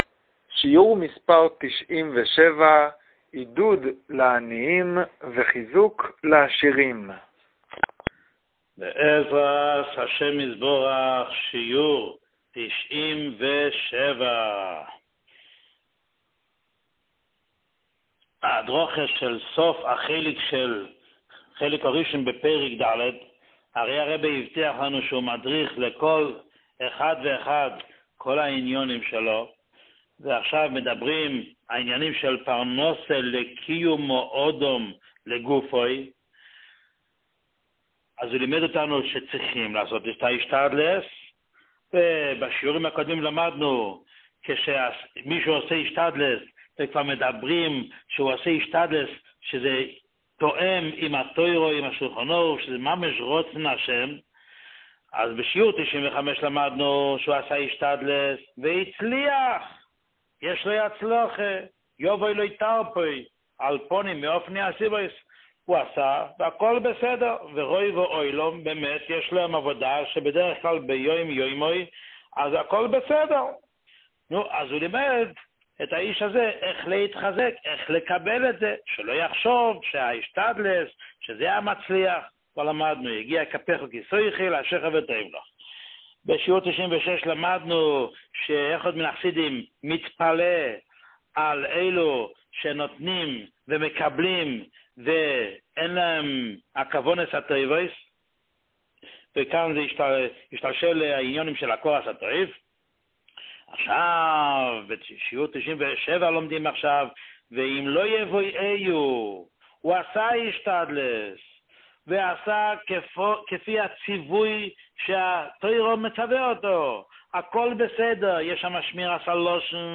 שיעור 97